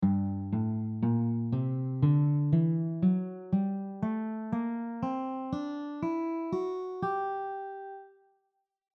G melodic minor scaleGABbCDEF#
G melodic minor scale
G-melodic-minor-scale.mp3